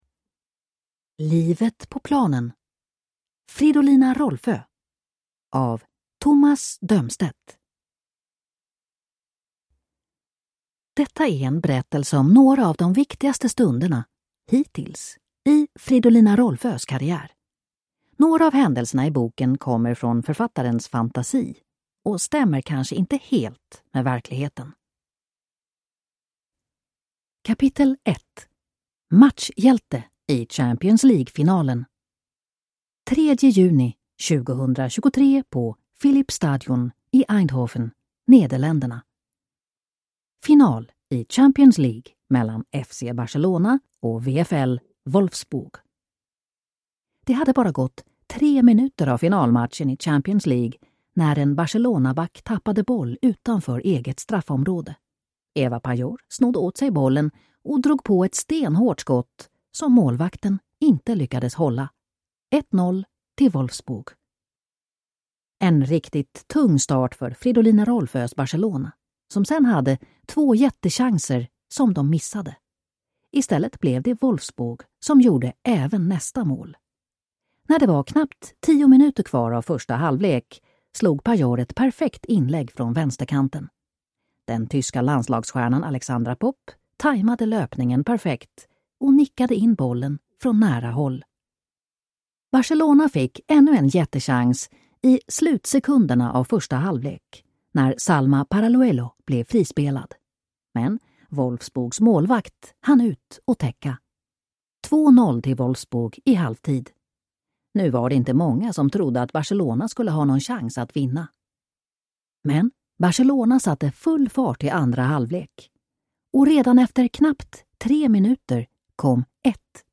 Livet på planen - Fridolina Rolfö (ljudbok) av Tomas Dömstedt